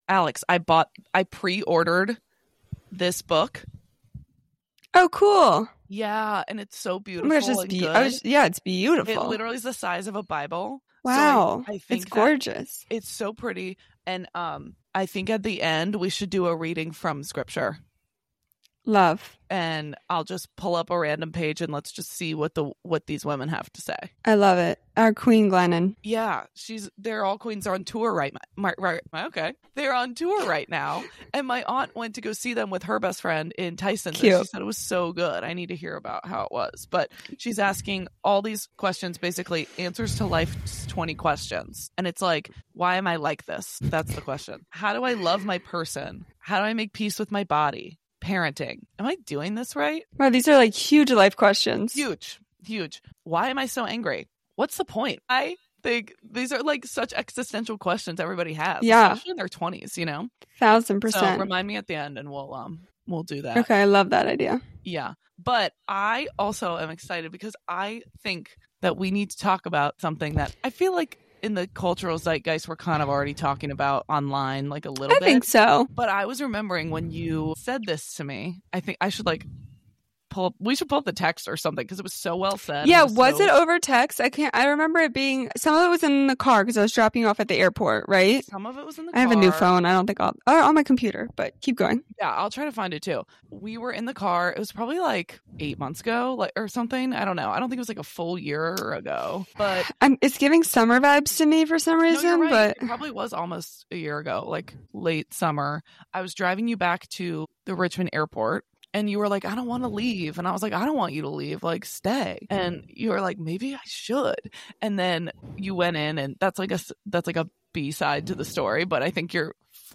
Part cozy confessional, part comic relief and fully devoted to doing less, feeling more and laying down while doing it.